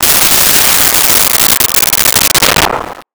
Missle 10
Missle 10.wav